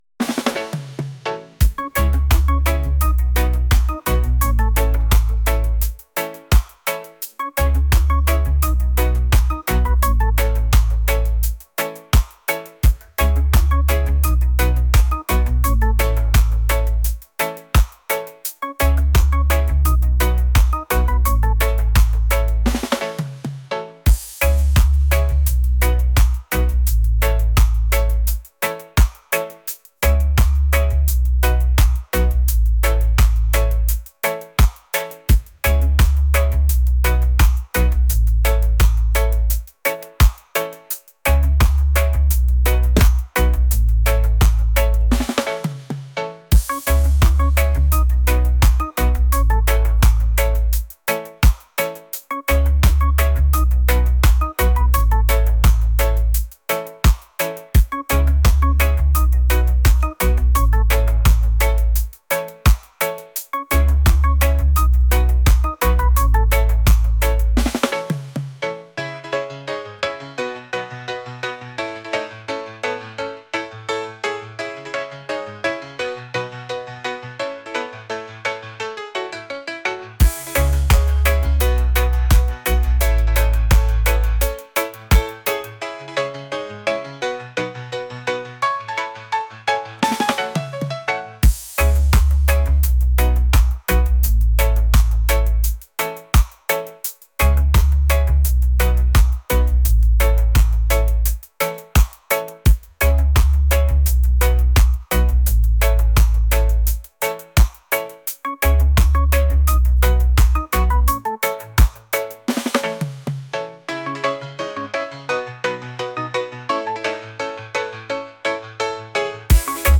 reggae | latin | folk